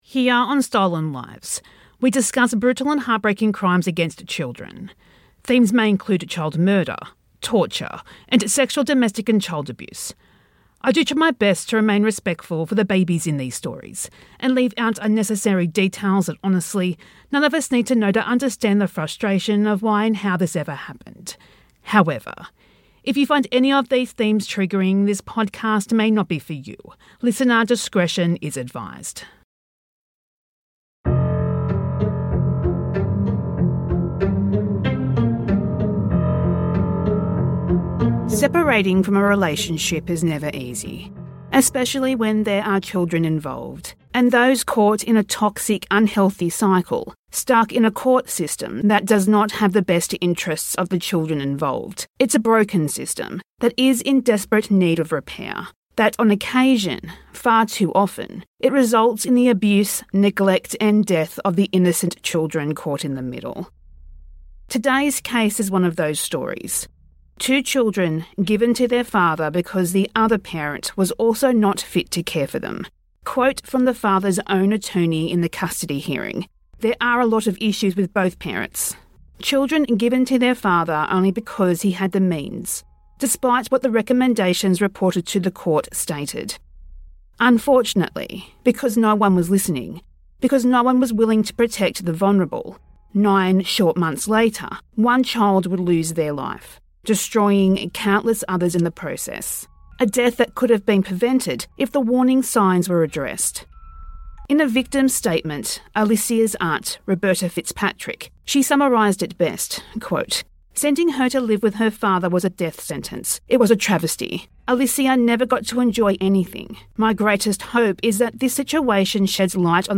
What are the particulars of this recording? This is a re-release with sped up audio and gaps of silence removed.